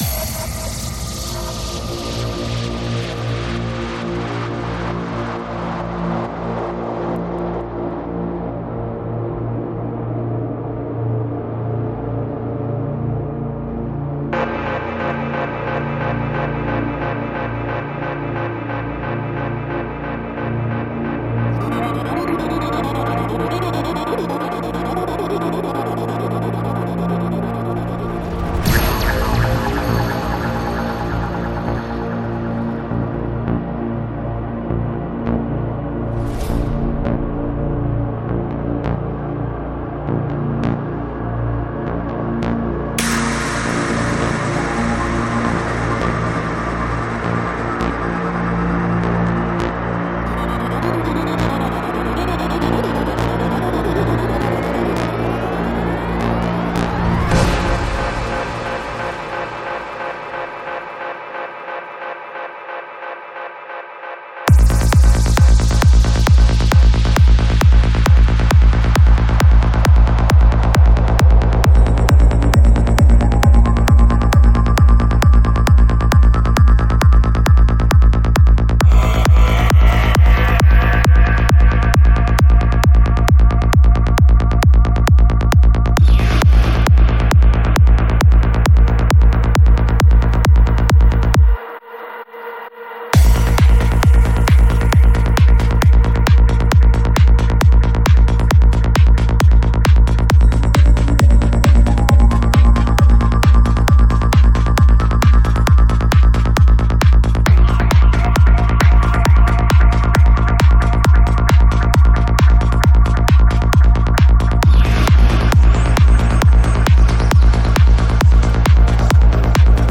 Жанр: Psychedelic
Psy-Trance Скачать 7.37 Мб 0 0 0